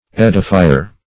Search Result for " edifier" : The Collaborative International Dictionary of English v.0.48: Edifier \Ed"i*fi`er\, n. 1.